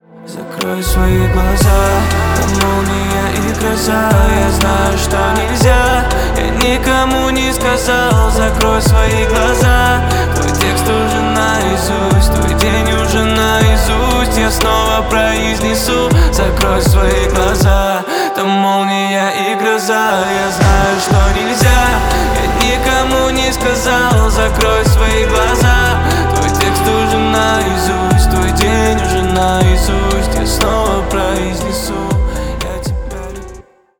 Поп Музыка
спокойные
тихие